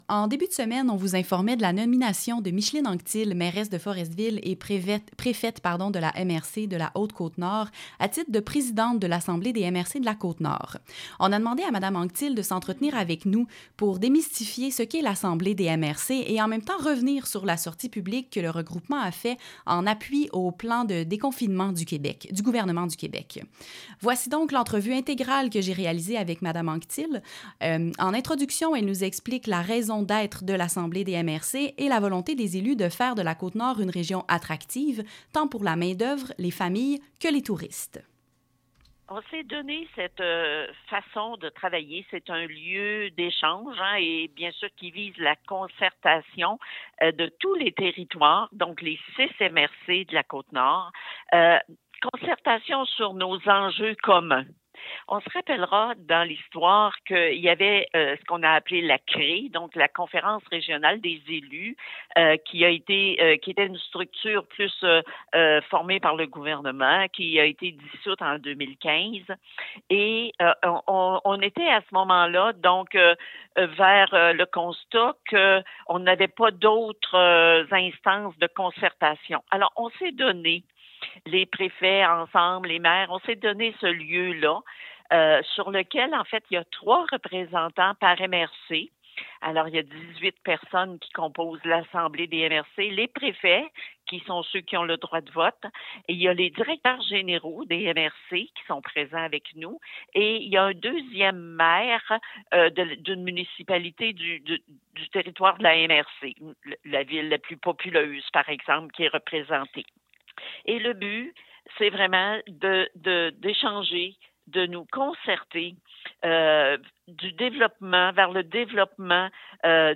Assemblée-MRC-diffusion-radio.mp3